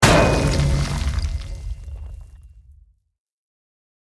smash_ground_heavy.ogg